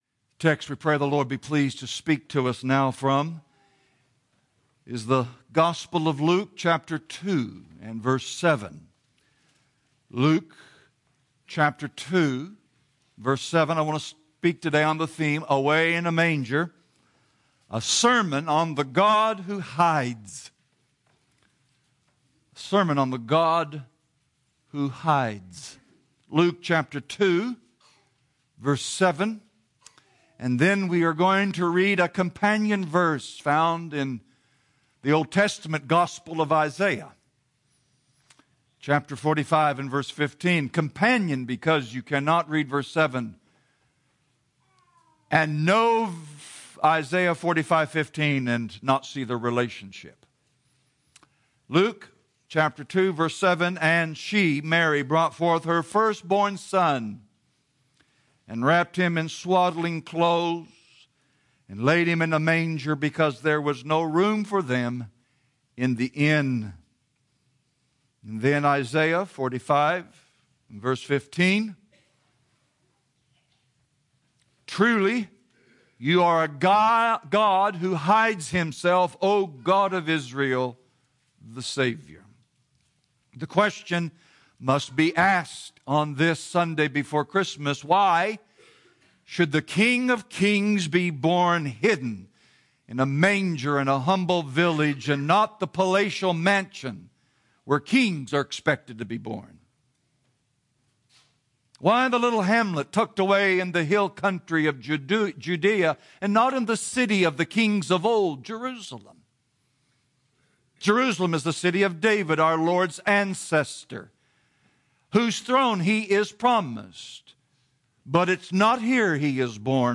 Away in a Manger: A Sermon on the God who Hides | Real Truth Matters